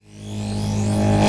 VEC3 Reverse FX
VEC3 FX Reverse 21.wav